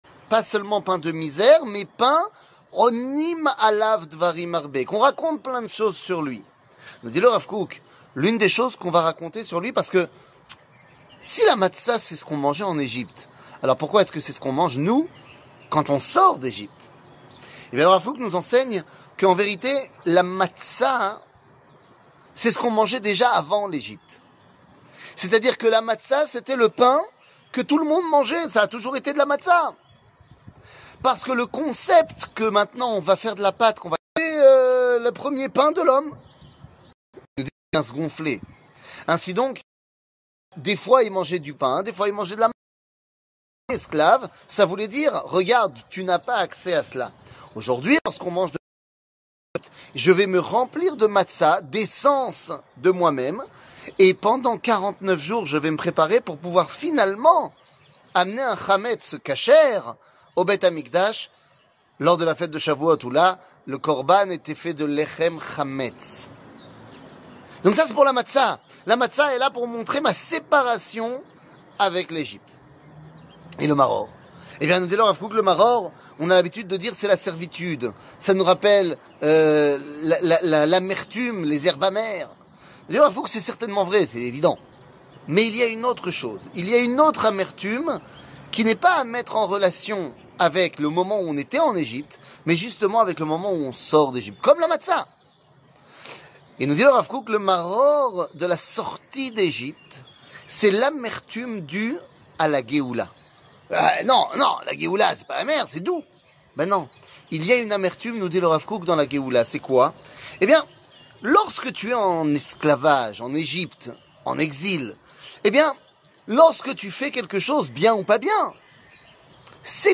Rav Kook, la Matza et le Maror, Olat Rehiya 00:02:58 Rav Kook, la Matza et le Maror, Olat Rehiya שיעור מ 21 מרץ 2023 02MIN הורדה בקובץ אודיו MP3 (2.71 Mo) הורדה בקובץ וידאו MP4 (5.57 Mo) TAGS : שיעורים קצרים